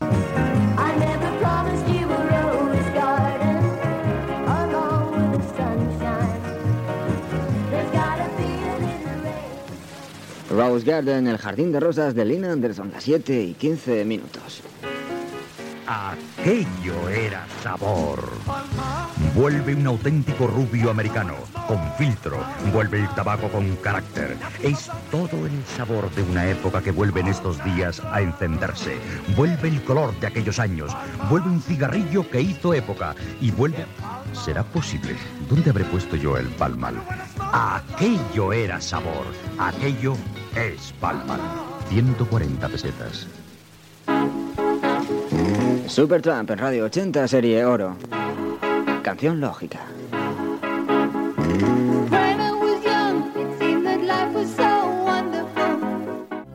Comiat d'una cançó, hora, publicitat i presentació d'una cançó.
Musical
FM